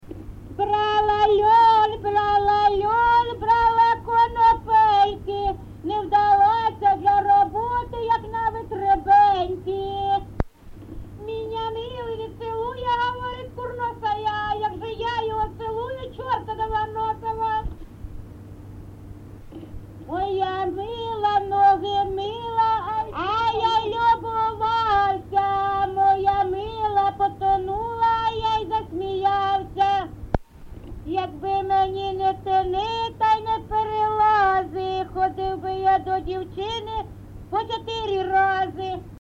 ЖанрТриндички
Місце записус. Гнилиця, Сумський район, Сумська обл., Україна, Слобожанщина